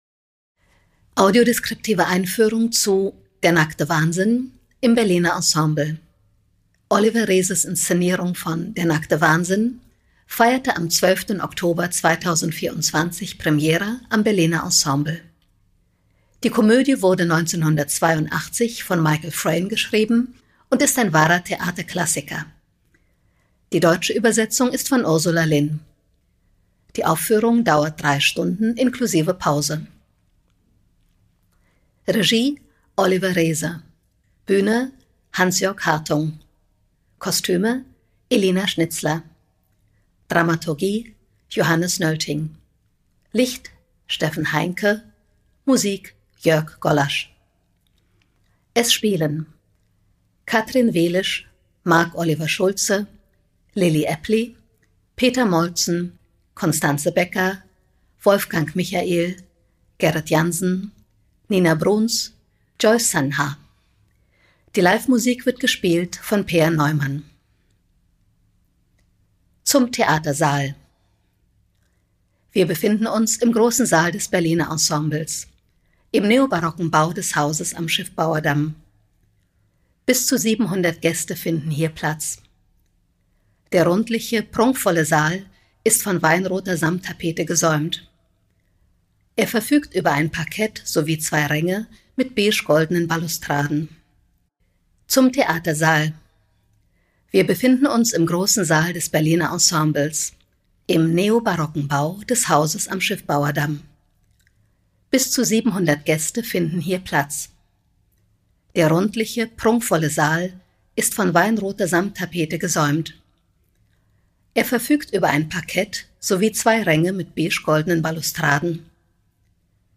Audiodeskriptive Einführung "Der nackte Wahnsinn"